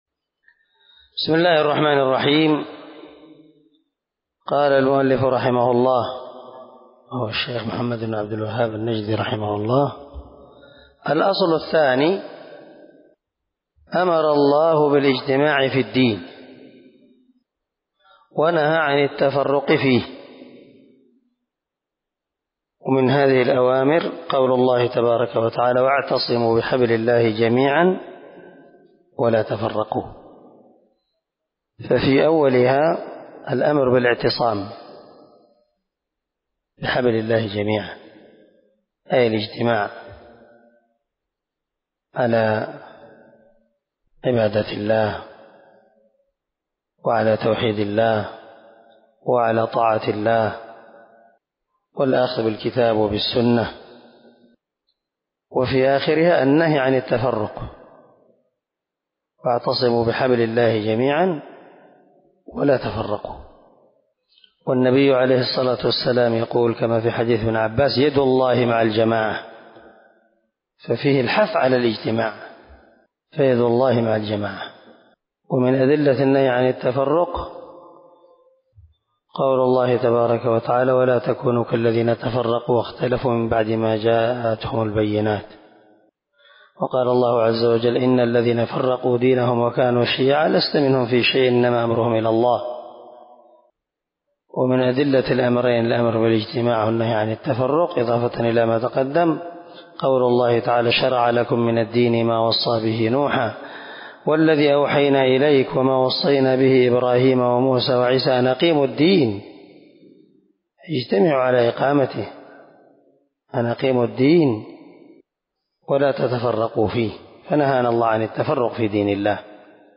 🔊 الدرس 2 من شرح الأصول الستة (الأصل الثاني)